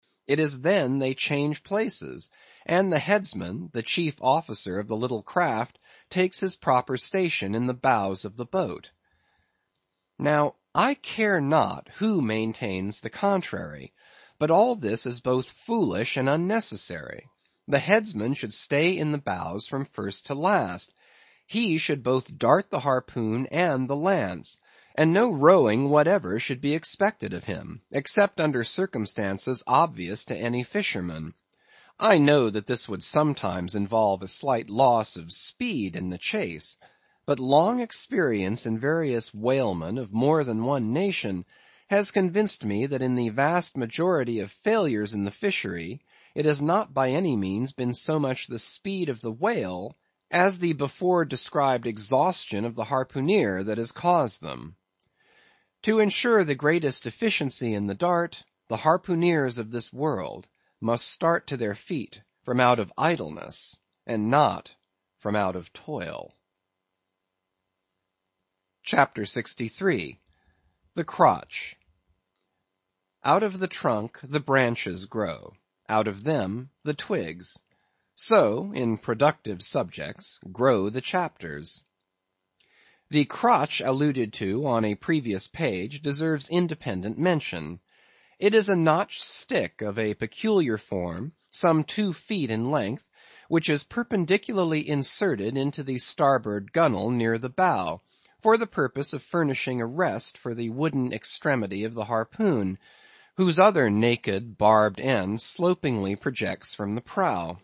英语听书《白鲸记》第606期 听力文件下载—在线英语听力室